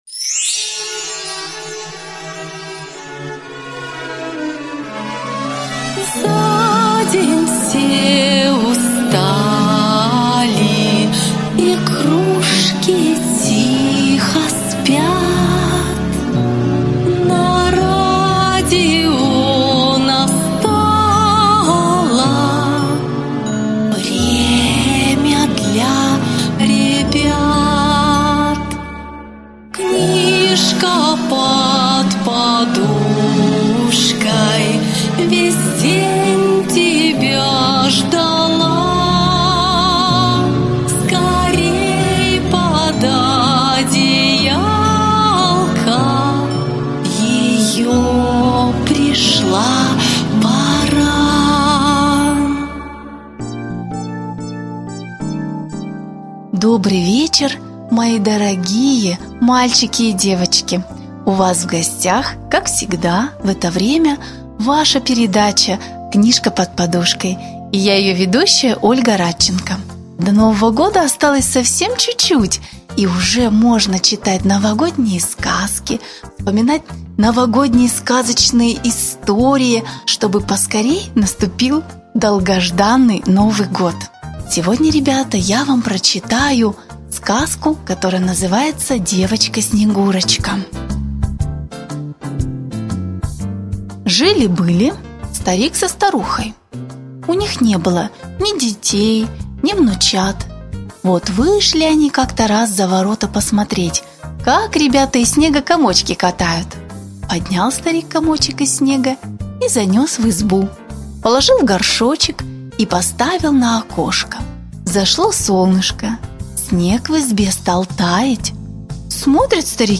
Девочка Снегурочка - аудиосказка Даля - слушать онлайн